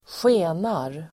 Uttal: [²sj'e:nar]